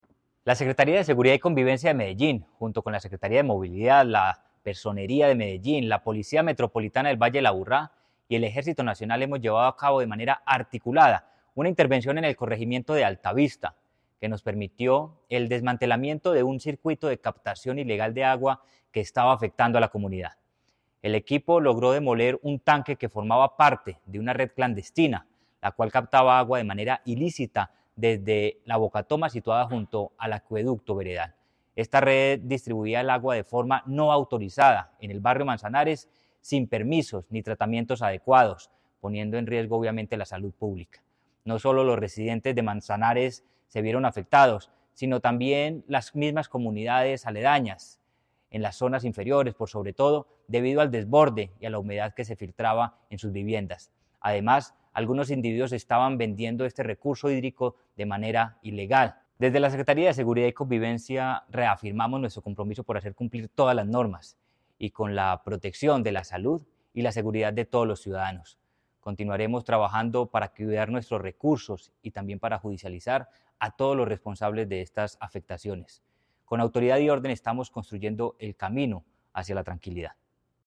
Palabras de Manuel Villa Mejía, secretario de Seguridad y Convivencia